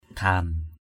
/ɡʱa:n/ (d.) dàm (voi). mâk lamân buh ghan mK lmN b~H GN bắt voi buộc dàm.